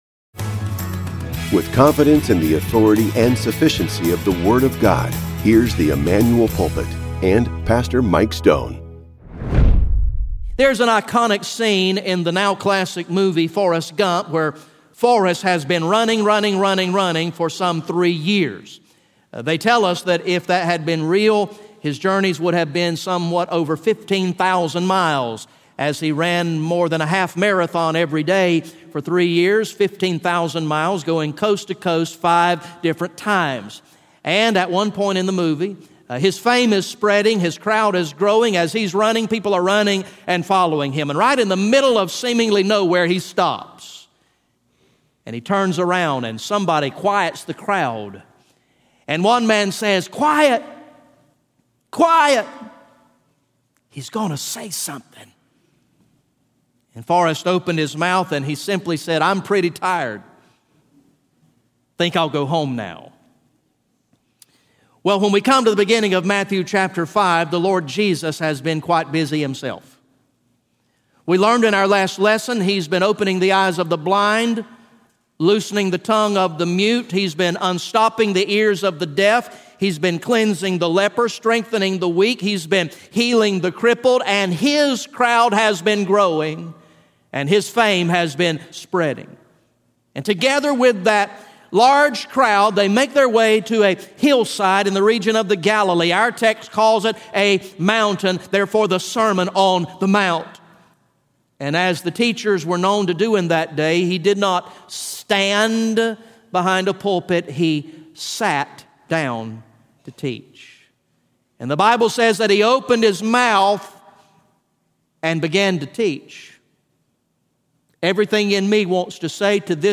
GA Message #14 from the sermon series entitled “King of Kings